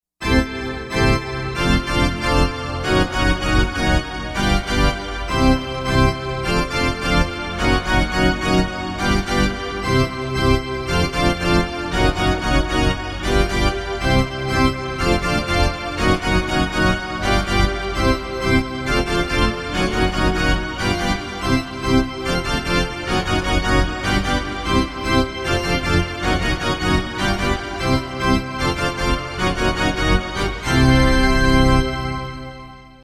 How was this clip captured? Tags: Princeton Hockey Baker Rink